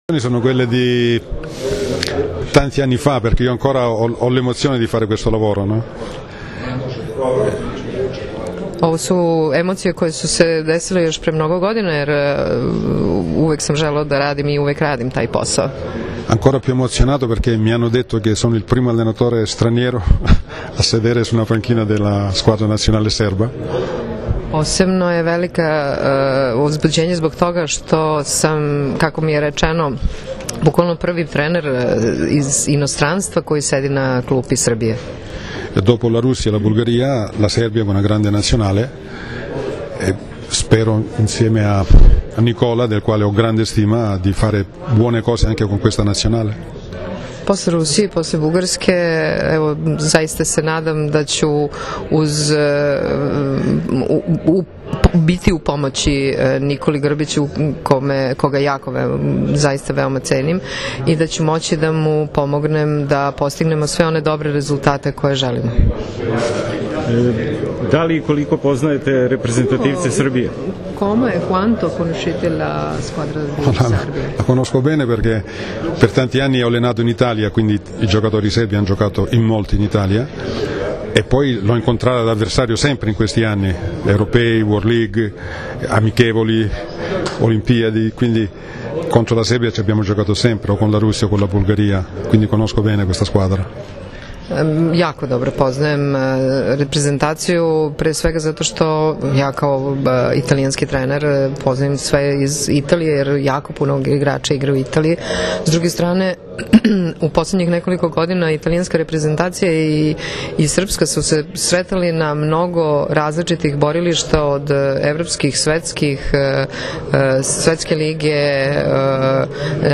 U beogradskom hotelu „Metropol“ održana je konferencija za novinare na kojoj je predstavljen Nikola Grbić, novi selektor muške seniorske reprezentacije Srbije.
IZJAVA